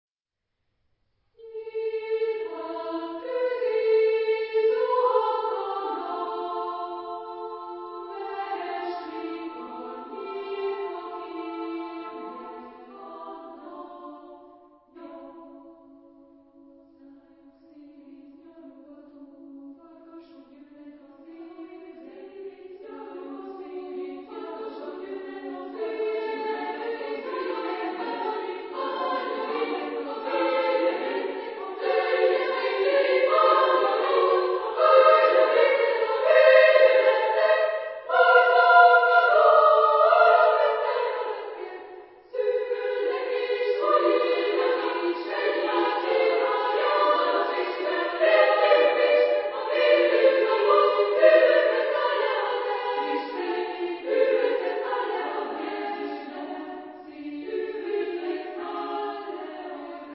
Genre-Style-Forme : Profane ; Chœur ; Suite
Type de choeur : SMA  (3 voix égales )